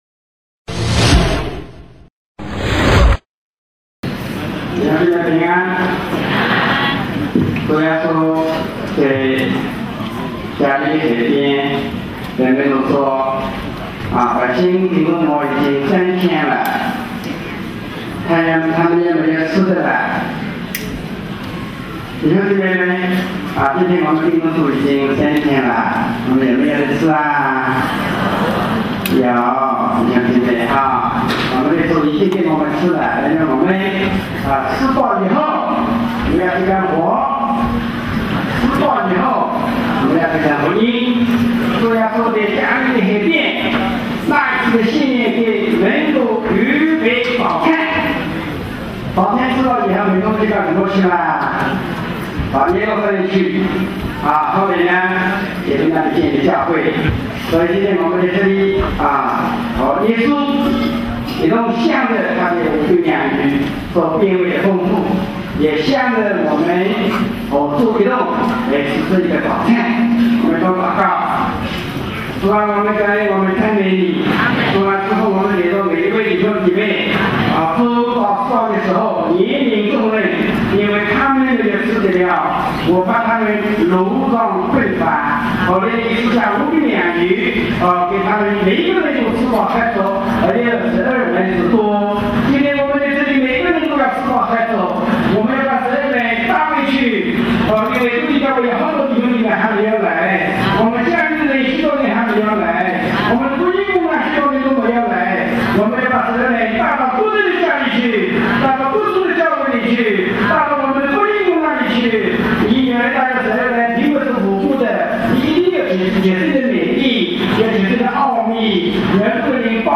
特会信息